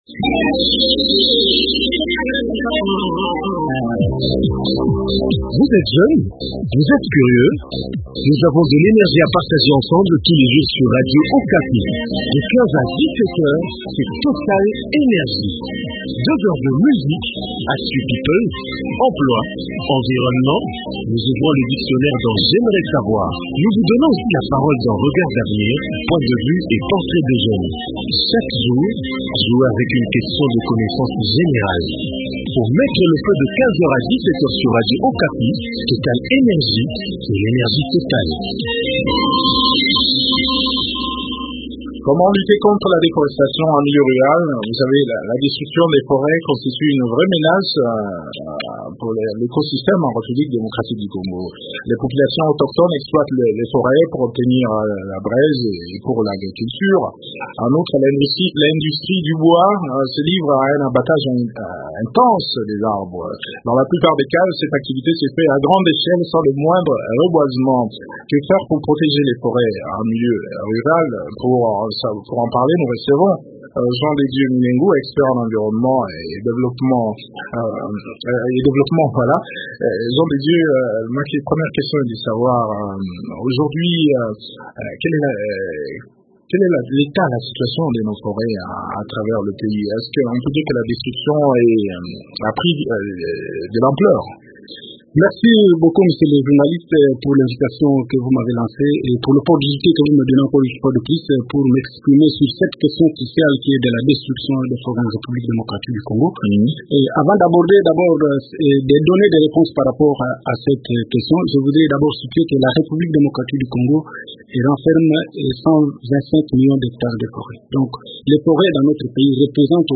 expert en environnement et développement décrypte le sujet au micro